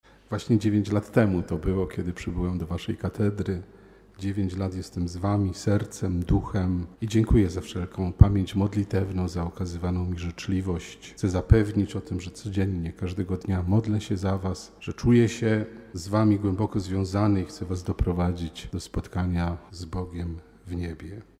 – Bardzo serdecznie dziękuje za pamięć i życzenia – mówił abp Józef Kupny.